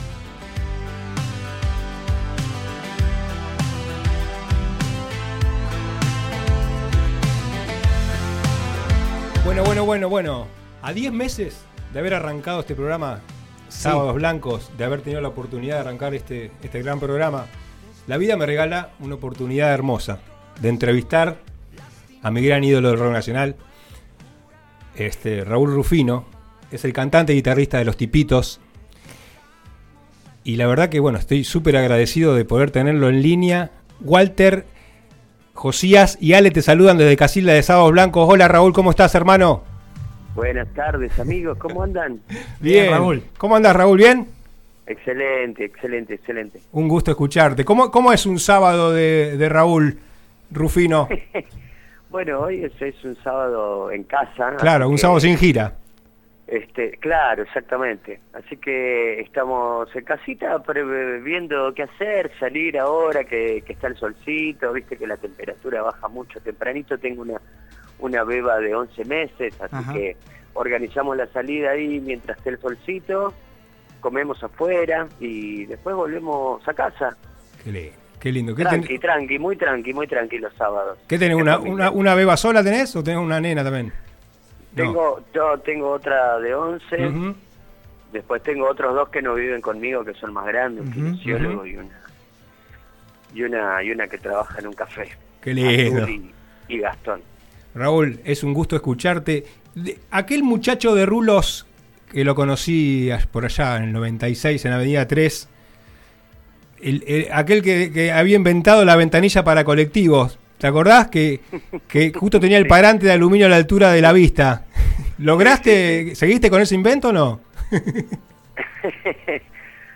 El músico Raúl Rufino, voz y guitarra de la conocida banda Los Tipitos, paso por los micrófonos del Programa «Sábados Blancos», de FM 107.3 – RADIO DEL SUR para contarnos su amplia trayectoria.